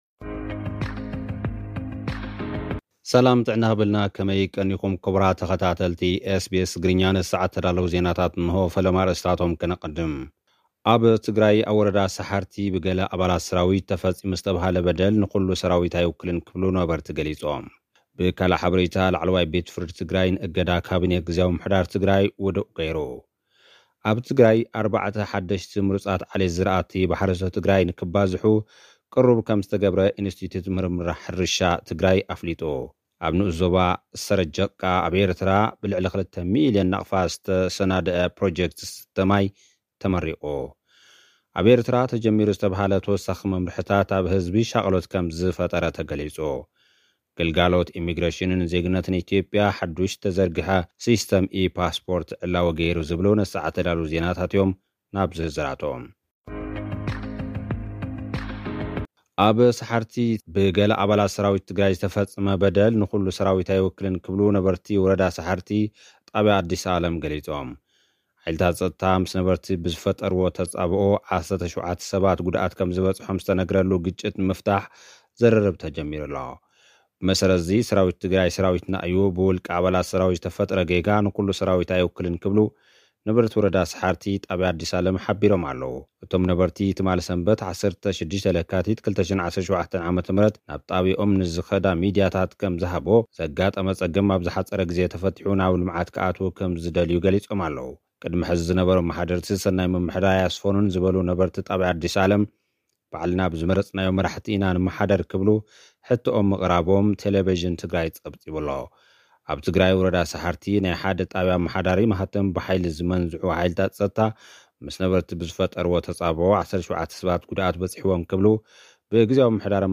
ኣብ ኤርትራ ተጀሚሩ ዝተብሃለ "ተወሳኺ መምርሒታት ክተት" ኣብ ህዝቢ ሻቕሎት ከም ዝፈጠረ ተገሊጹ። (ጸብጻብ)